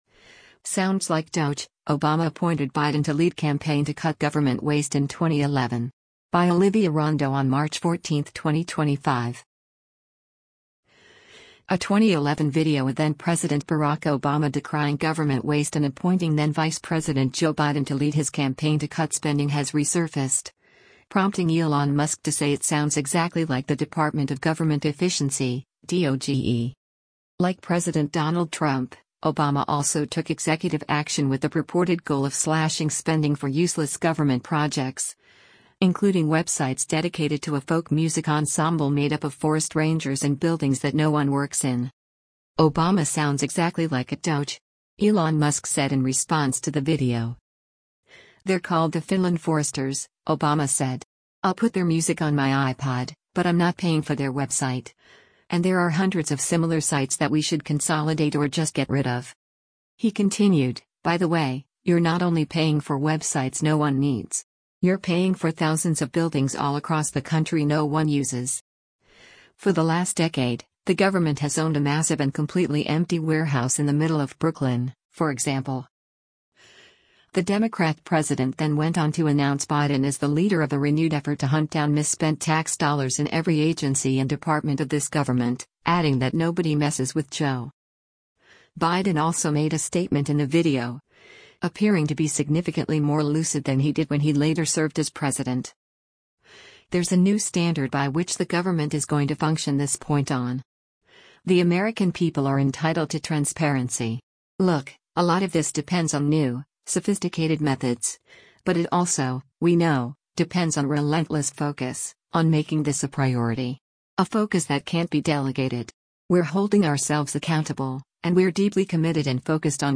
Biden also made a statement in the video, appearing to be significantly more lucid than he did when he later served as president: